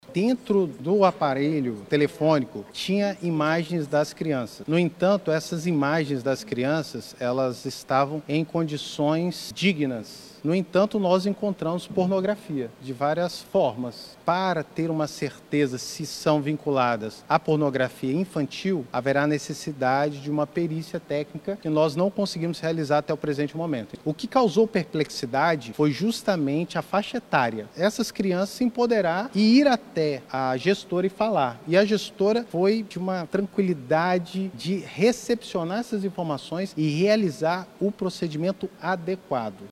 SONORA02_POLICIA.mp3